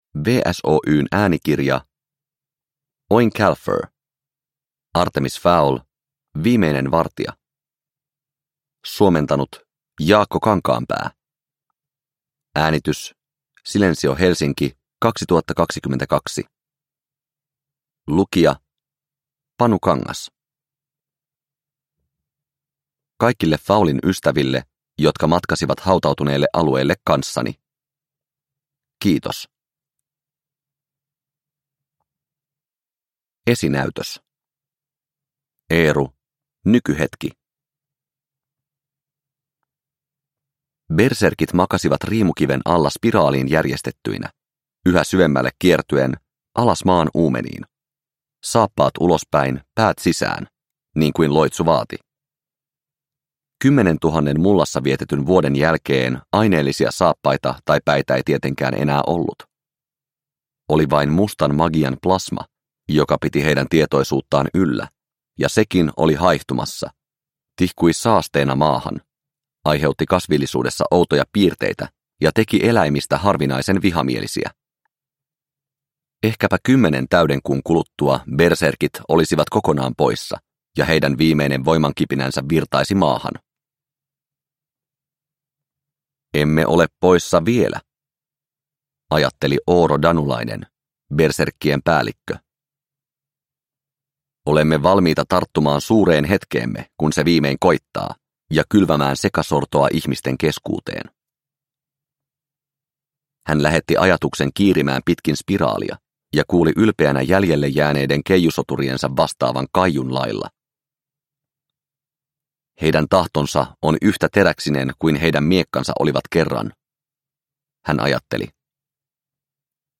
Artemis Fowl: Viimeinen vartija – Ljudbok – Laddas ner